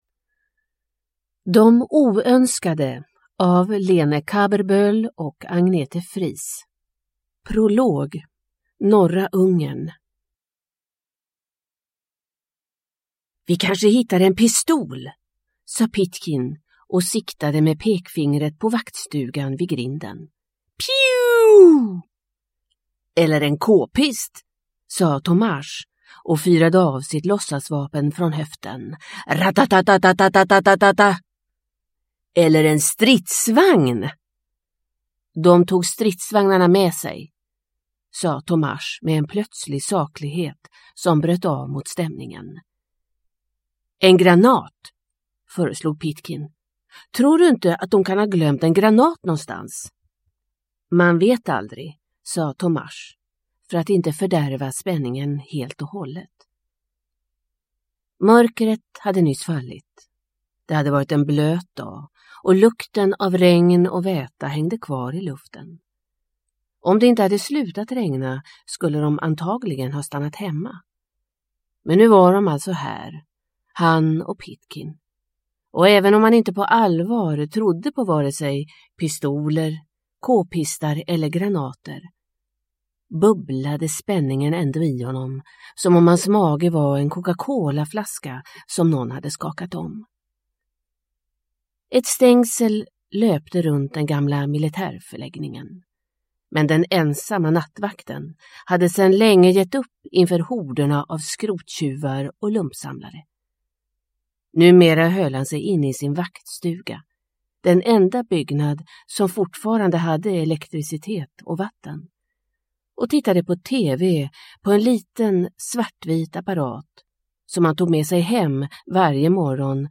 De oönskade – Ljudbok – Laddas ner